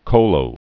(chōlō)